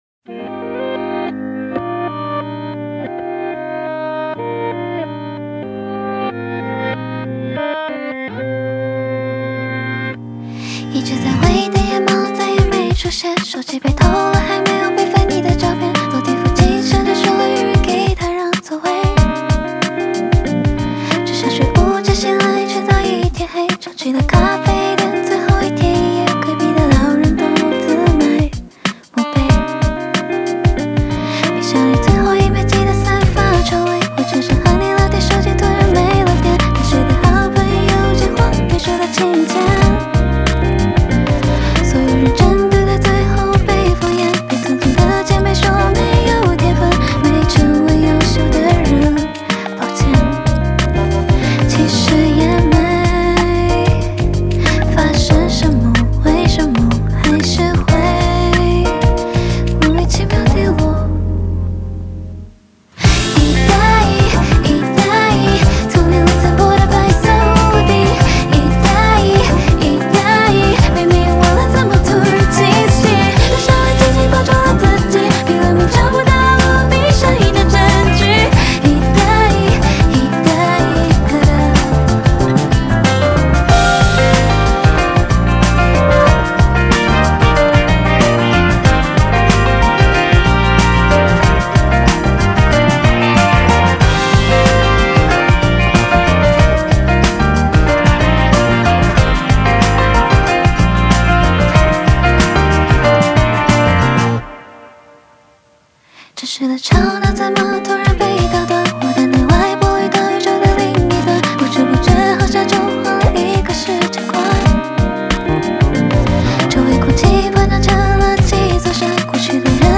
Ps：在线试听为压缩音质节选，体验无损音质请下载完整版